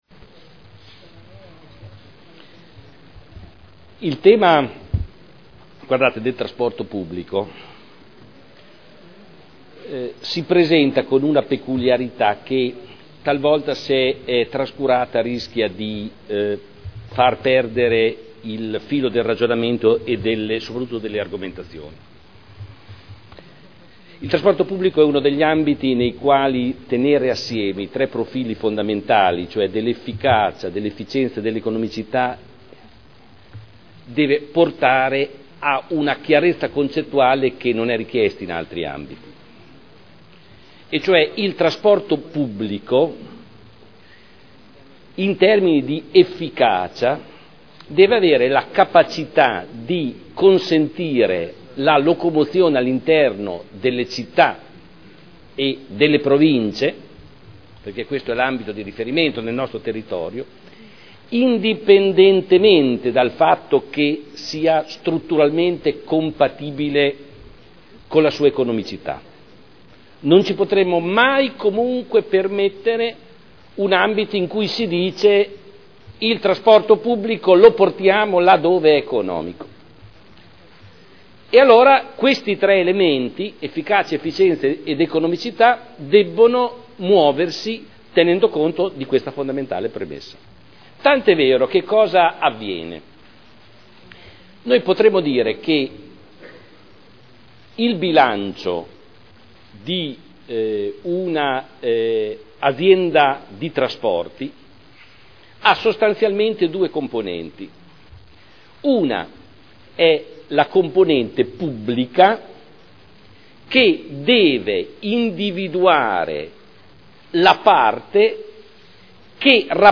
Seduta del 21/02/2011. Indirizzi per la gestione del trasporto pubblico locale a seguito del patto per il trasporto pubblico regionale e locale in Emilia Romagna per il triennio 2011/2013 – aumenti tariffari per il Comune di Modena – Approvazione discussione